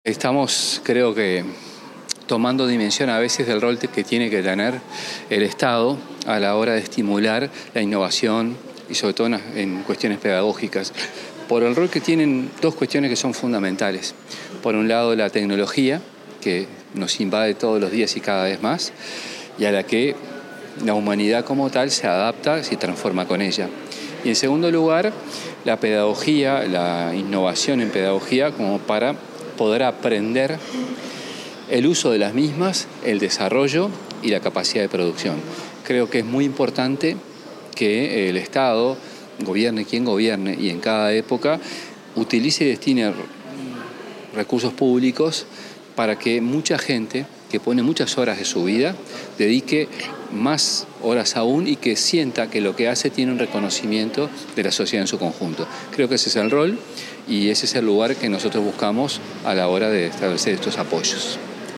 Declaraciones del ministro de Educación y Cultura, José Carlos Mahía
Antes de participar de la ceremonia de premiación Nodo 2025, el ministro de Educación y Cultura, José Carlos Mahía, dialogó con la prensa.